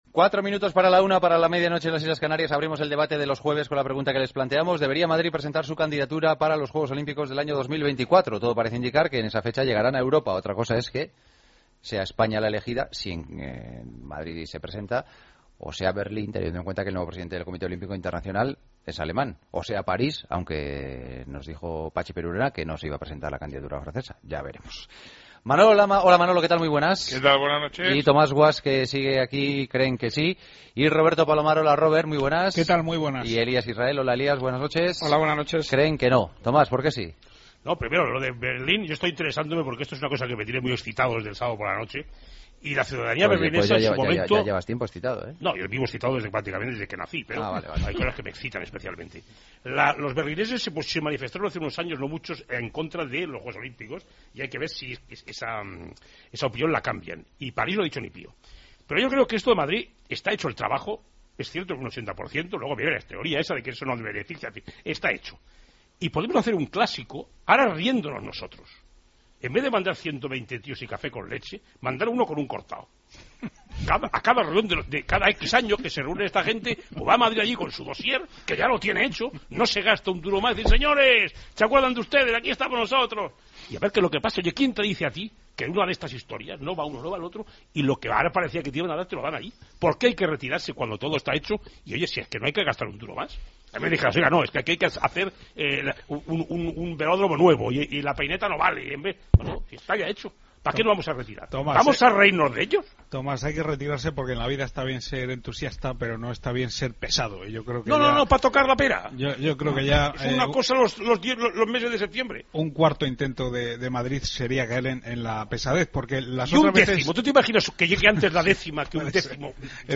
El debate de los jueves: ¿Debería presentarse Madrid a los JJ.OO de 2024?